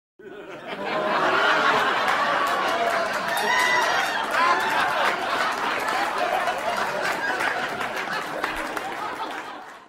laugh-track-mp3-1.mp3